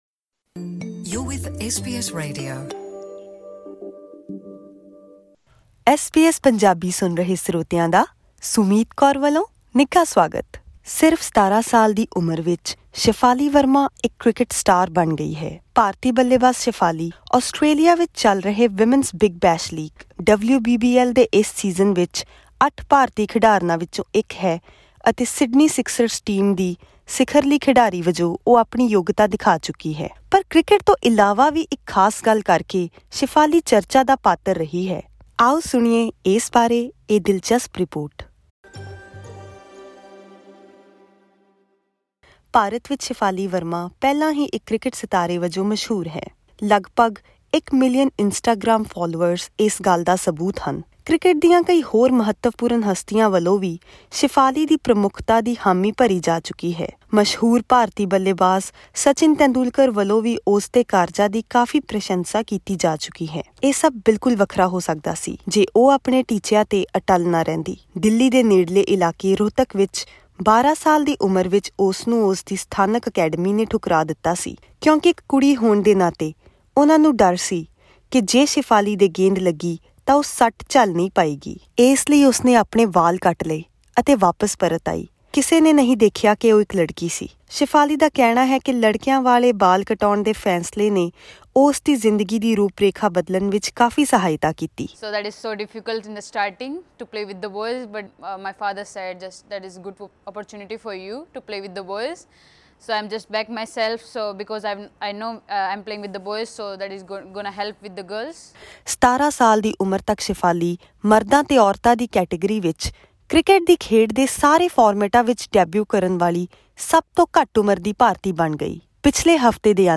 Her deeds have earned high praise from the most famous Indian batter of all time Sachin Tendulkar [[SAT-chin ten-DOOL-kar]].